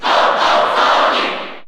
Crowd cheers (SSBU) You cannot overwrite this file.
Sonic_Cheer_Japanese_SSBU.ogg